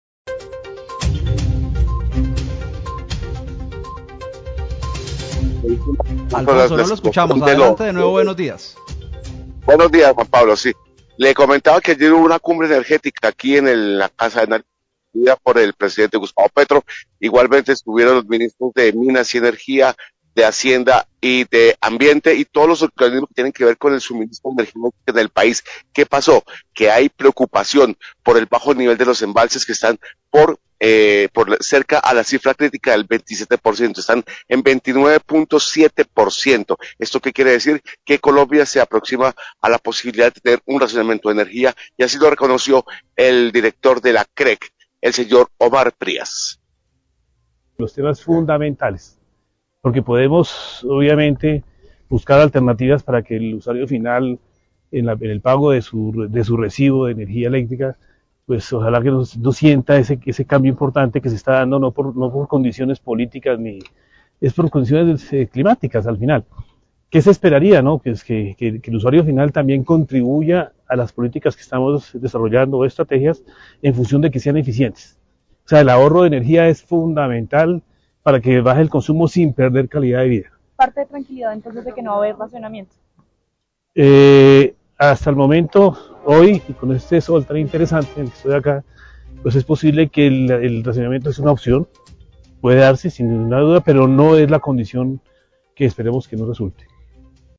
Radio
En cumbre energética, el director de la CREG, Omar Prias, manifestó preocupación por el bajo nivel de los embalses del país e indicó que el racionamiento de energía continúa siendo una opción. Se refirió a los cambios en la tarifa de energía por la coyuntura climática y realizó un llamado al ahorro de energía.